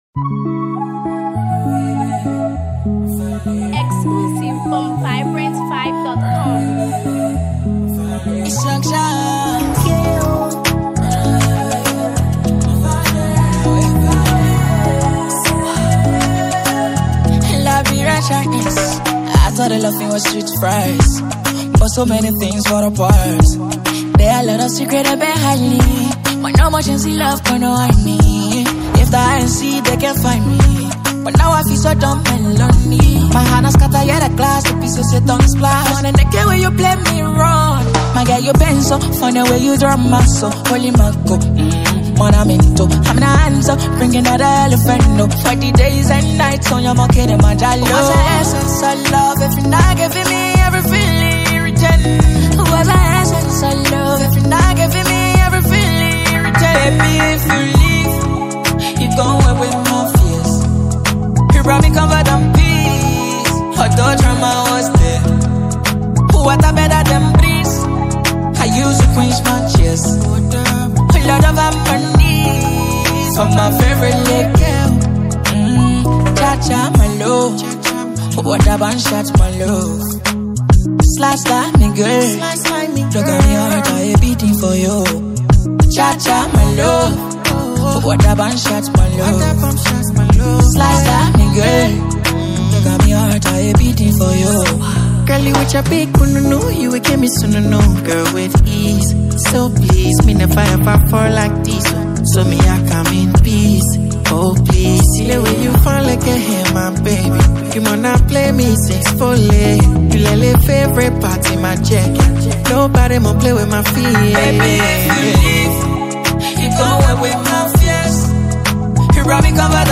smooth vocalist